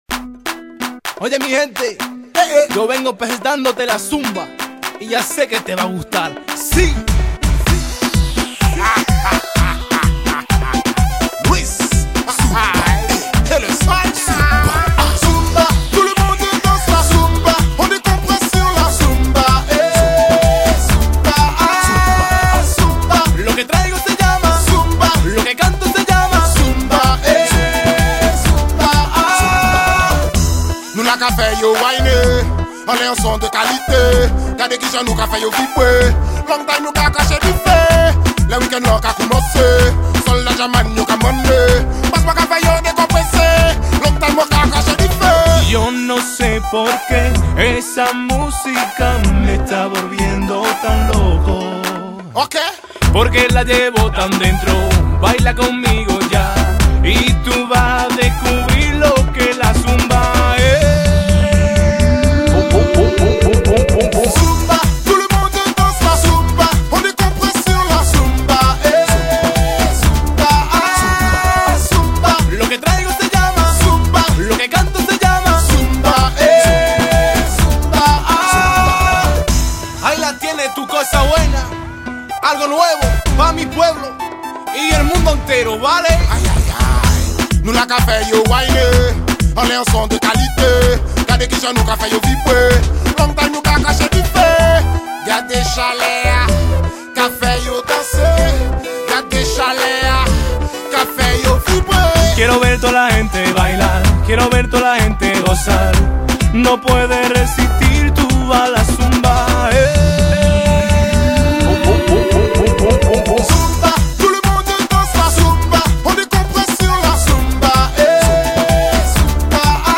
برای رقص زومبا و ورزش زومبا فیتنس